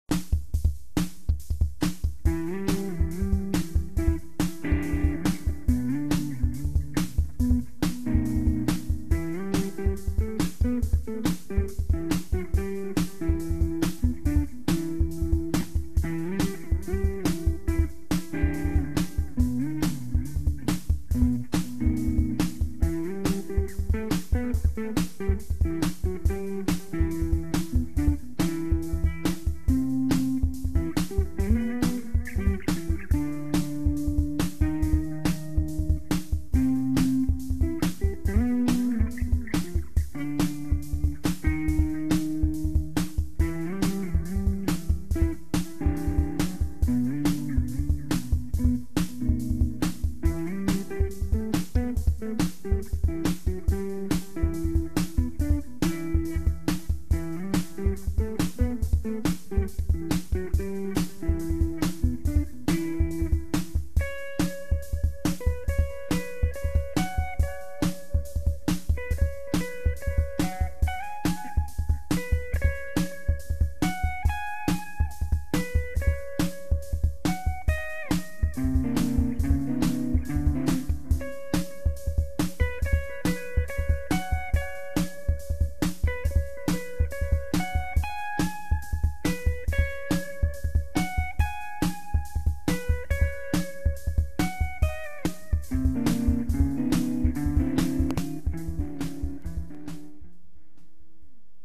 ＢＯＳＳのＦＤＲ－１を再度弾いてみる
デラリバモデリング.wma
店舗に無かったのでフェンダーのツインリバーブに繋いで。
このＦＤＲ－１をプリアンプとして鳴らしてみました。
そうしたら、リアル感というか、ソックリそのままＦＤＲ－１の音となり
とても良いサウンドでした。